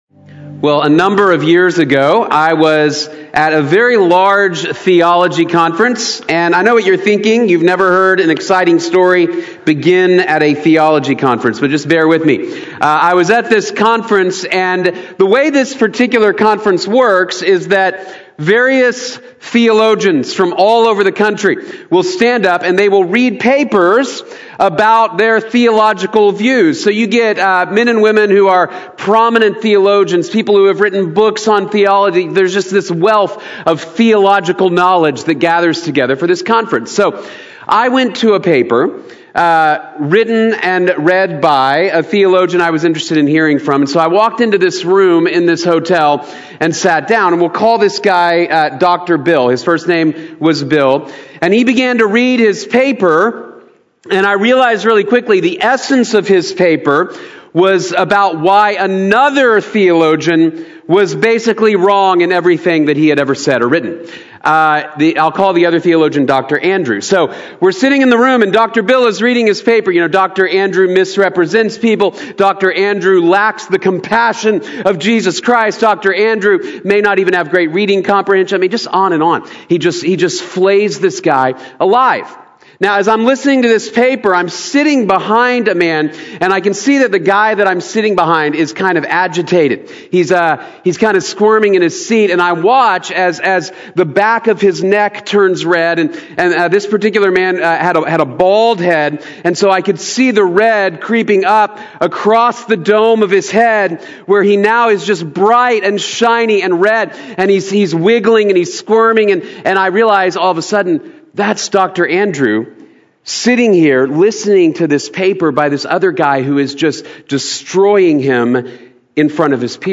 La derecha con Dios | Sermón | Iglesia Bíblica de la Gracia